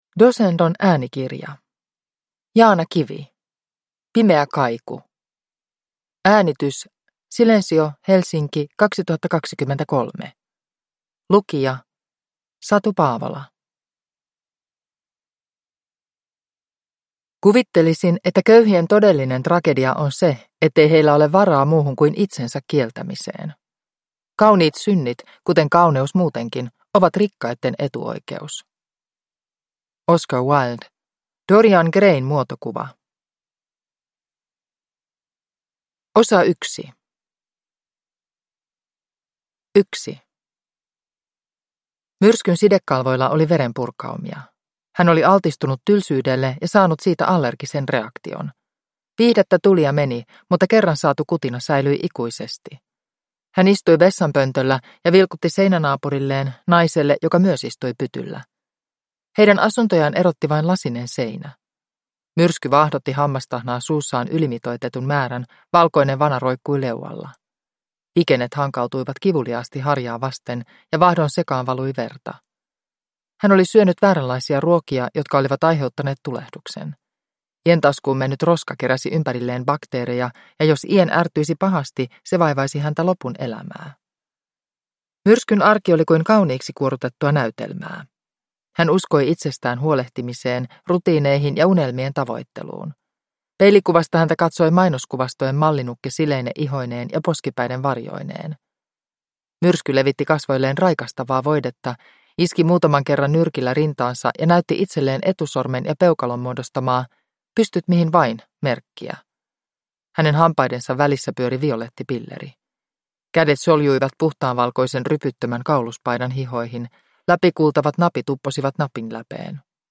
Pimeä kaiku – Ljudbok – Laddas ner